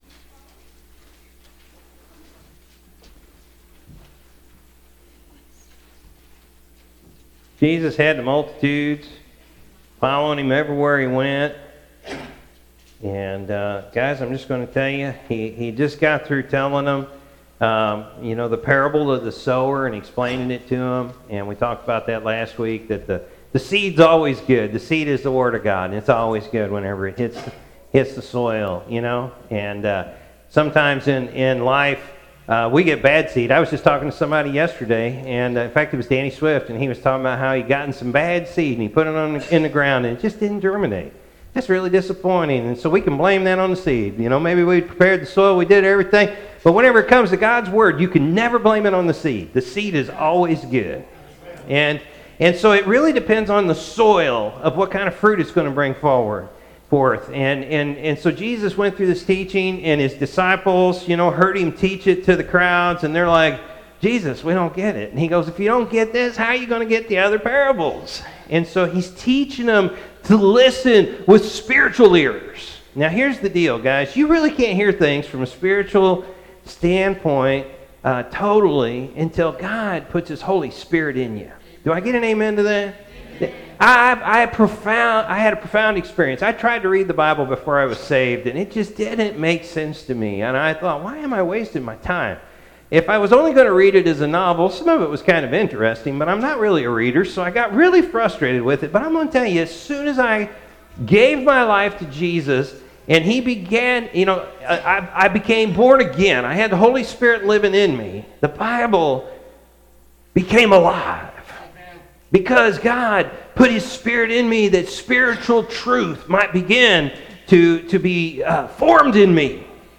Aug.-2-2020-morning-service.mp3